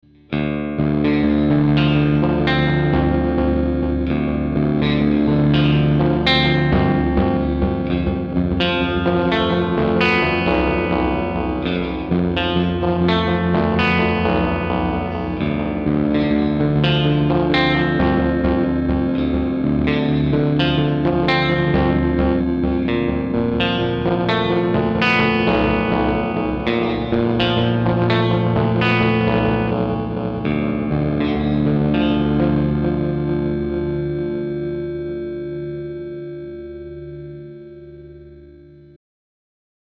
Soundbeispiele des Walrus Audio Mako D1
Alle Beispiele wurden mit meiner Fender Stratocaster und meinem Mesa Boogie Mark V 25 über den CabClone D.I. aufgenommen.